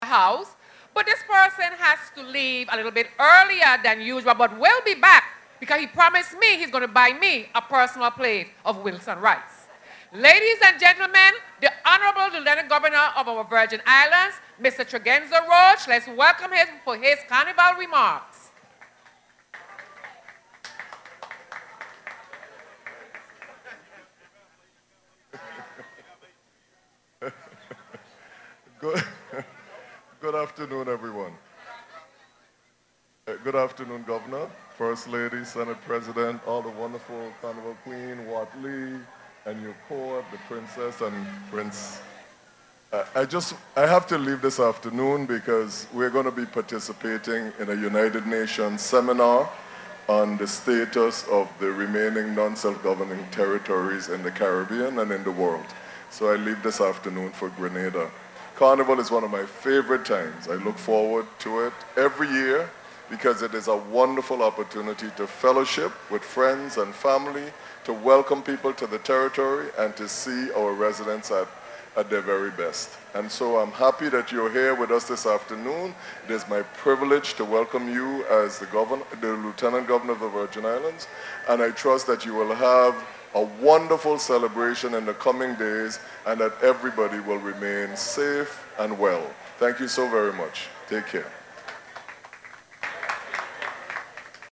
CHARLOTTE AMALIE—Governor Albert Bryan Jr. and Lt. Governor Tregenza Roach opened the St. Thomas Food, Arts and Crafts Fair this Wednesday which showcased the growing variety and diversity of Virgin Islands culture.
Listen to Lt. Governor Roach’s remarks